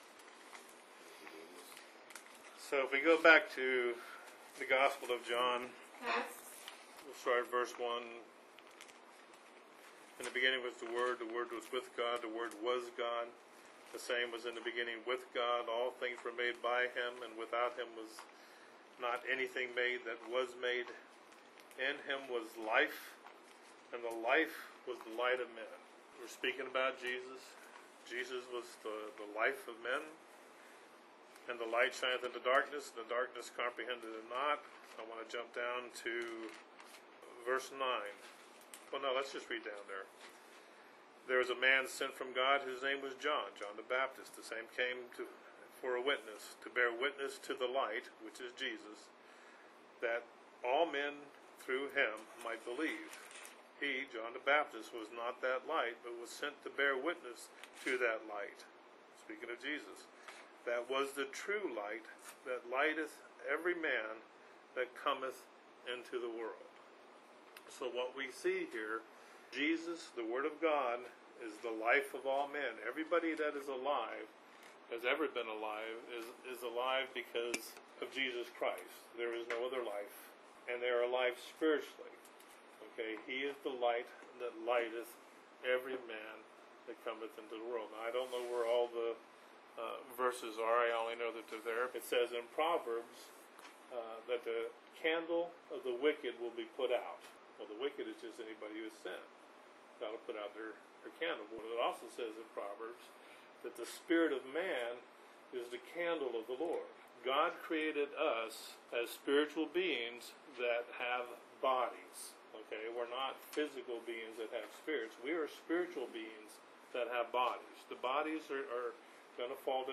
Sorry if the volume is low.
mens-meeting-predestination.mp3